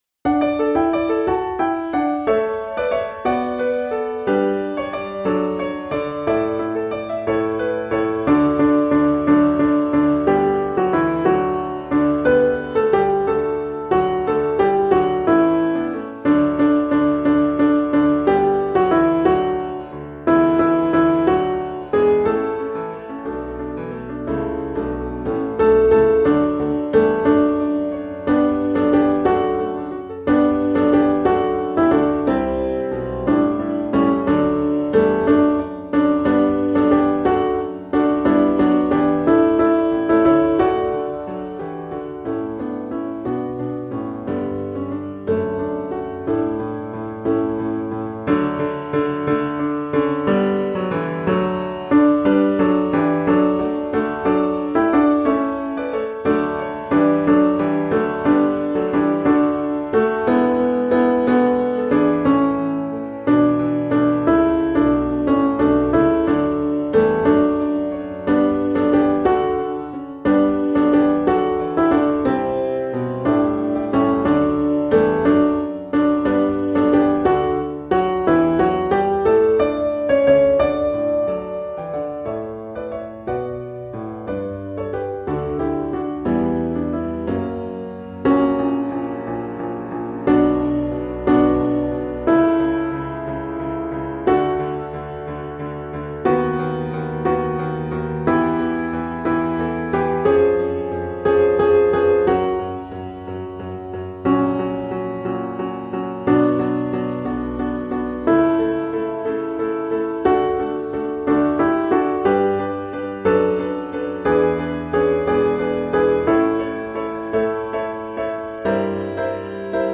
Joy Overflowing – Alto